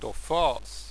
tÕ fîj, fwtÒj[]
La prononciation ici proposée est la prononciation qui a cours actuellement, en milieu scolaire, en France.